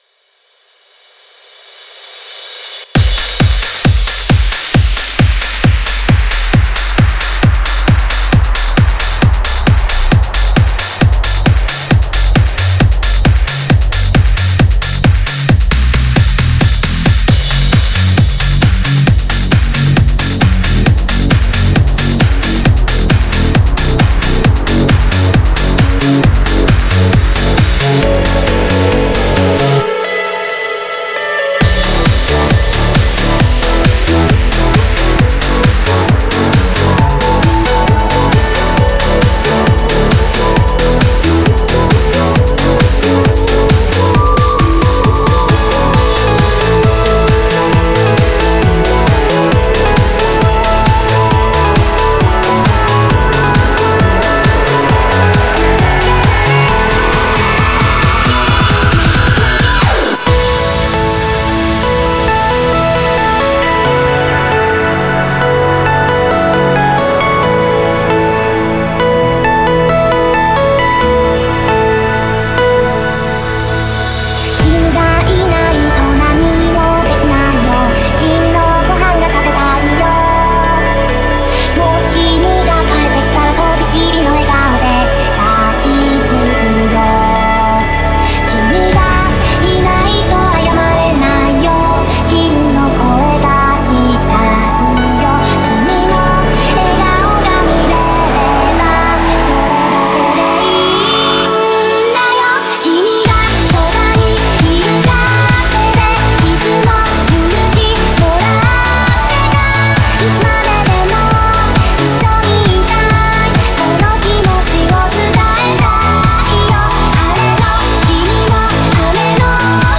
Genre(s): Trance